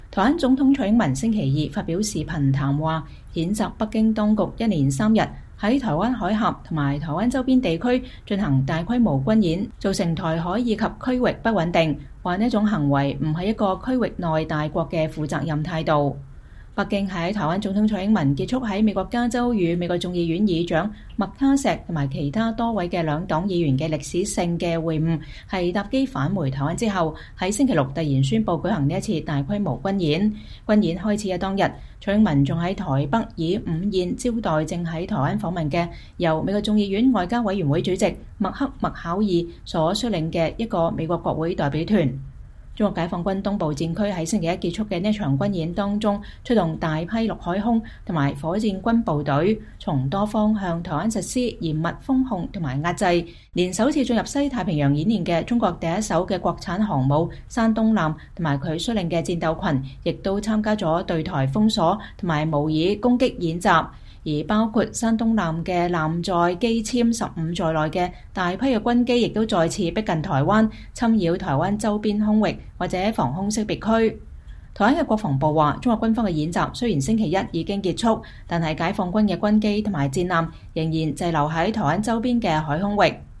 台灣總統蔡英文星期二（4月11日）發表視頻談話，譴責北京當局一連三天在台灣海峽以及台灣周邊地區進行大規模軍演造成台海以及區域不穩定，稱這種行為“不是一個區域內大國的負責任態度”。